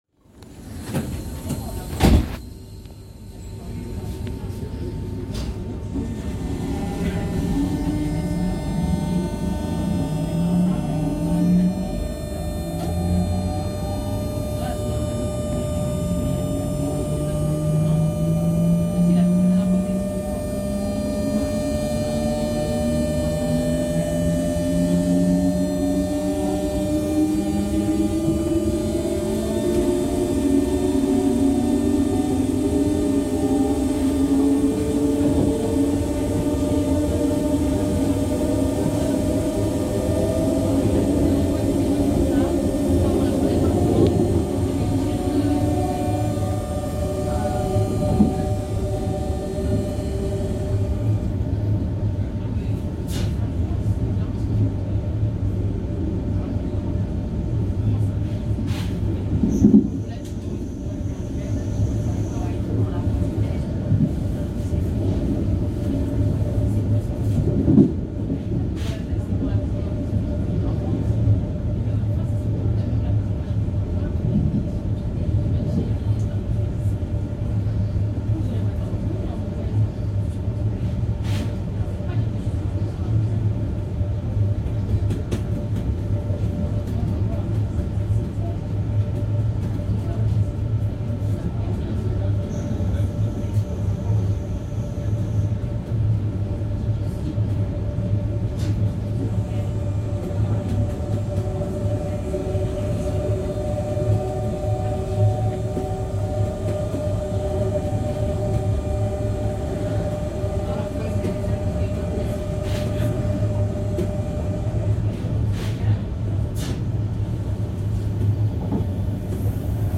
Z 20500 출발
Z 20500 시승